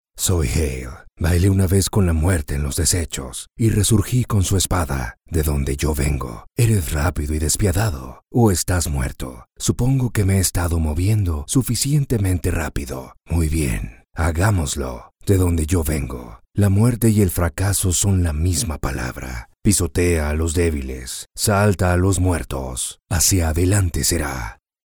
Jeux vidéo
Âge vocal : 25 à 65 ans
Styles : chaleureux, élégant, naturel, conversationnel, vendeur, corporatif, juridique, profond.
Équipement : Neumann TLM 103, Focusrite Scarlett, Aphex Channel, Source Connect